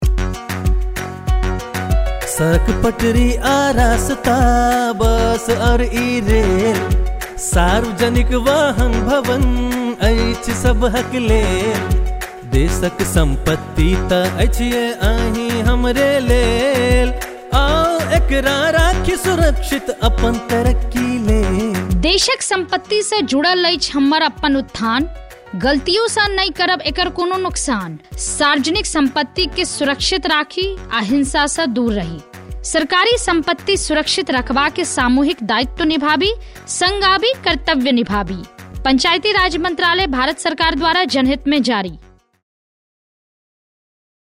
121 Fundamental Duty 9th Fundamental Duty Safeguard public property Radio Jingle Maithili